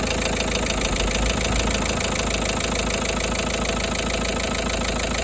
Klapavý zvuk, Logan 1.5dci
Môj názor je,že podľa zvuku je to klepanie ventilov.